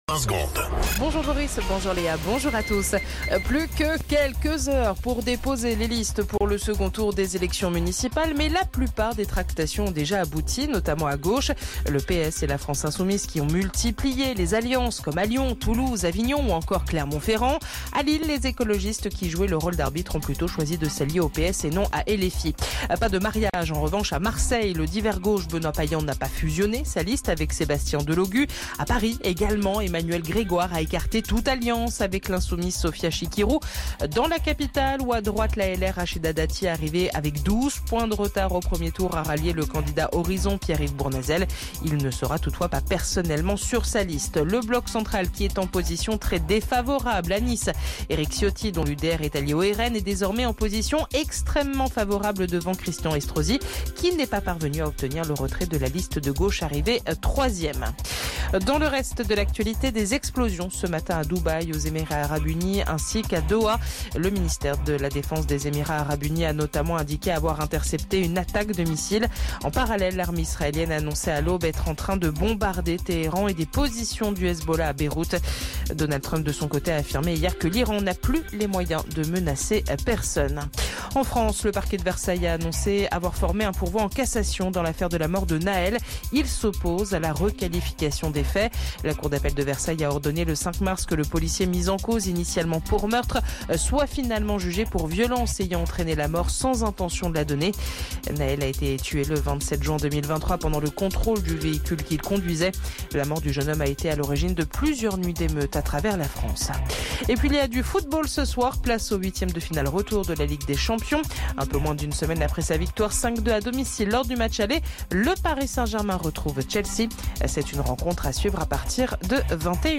Flash Info National 17 Mars 2026 Du 17/03/2026 à 07h10 .